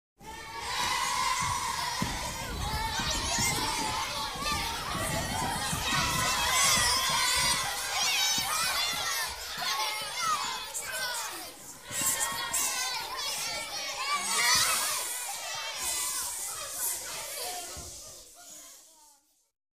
Недовольные вопли толпы детей в закрытом пространстве